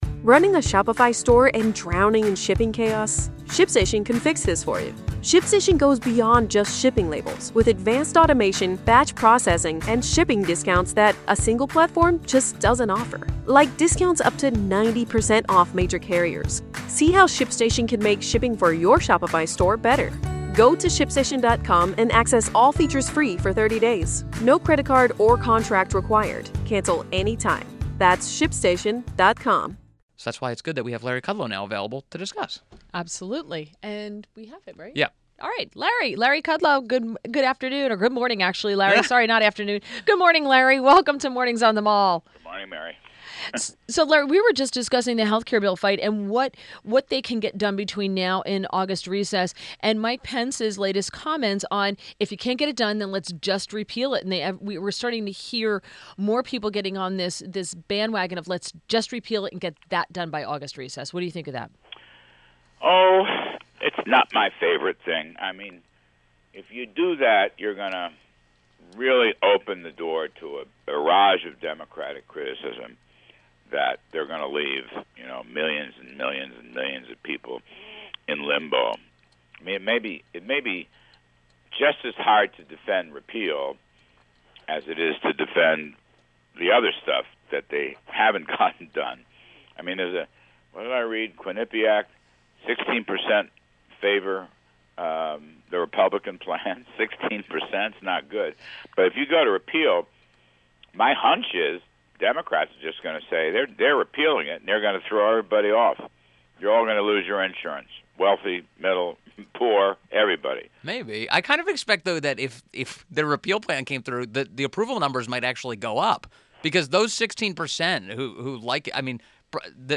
WMAL Interview - LARRY KUDLOW 07.11.17